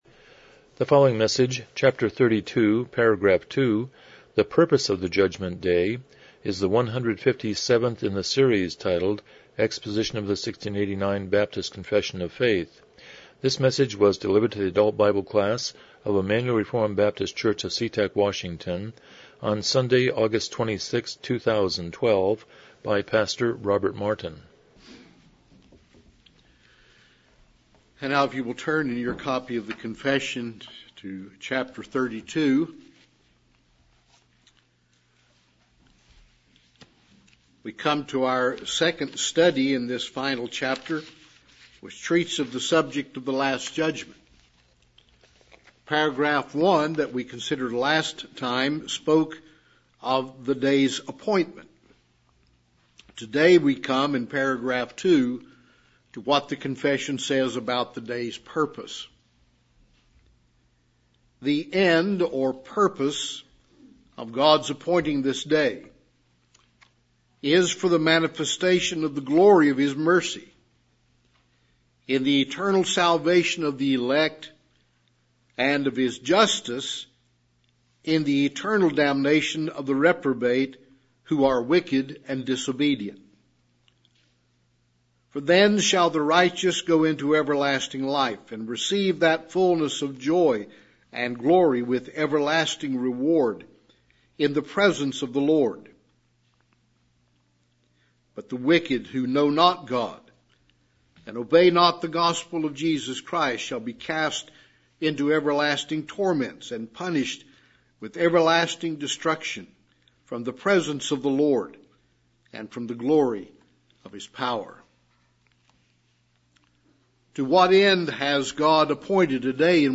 1689 Confession of Faith Service Type: Evening Worship « 11 Lord of the Sabbath 11 Proverbs 3:19-20